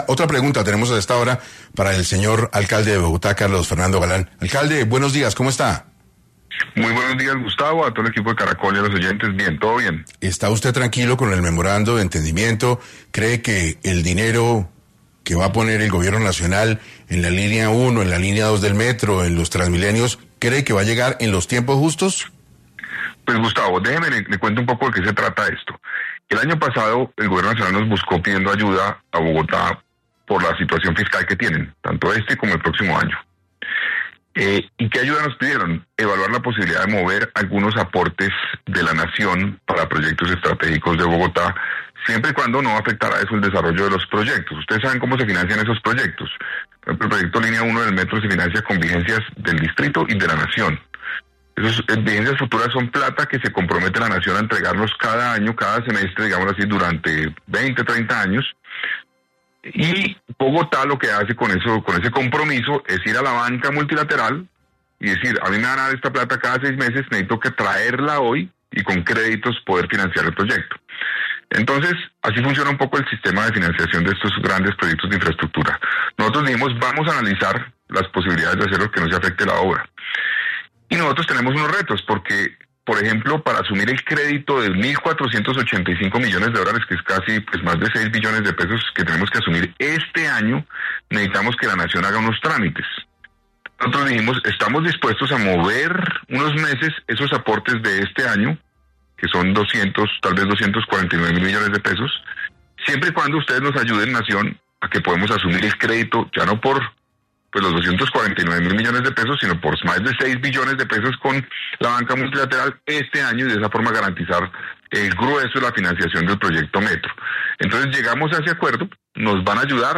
Carlos Fernando Galán pasó por 6AM para abordar los avances de la obra en desarrollo del Metro de Bogotá.
En este orden de ideas, el alcalde pasó por los micrófonos de 6AM, para profundizar en el tema.